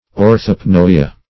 Search Result for " orthopnoea" : The Collaborative International Dictionary of English v.0.48: Orthopnoea \Or`thop*n[oe]"a\, Orthopny \Or*thop"ny\, n. [L. orthopnoea, Gr.
orthopnoea.mp3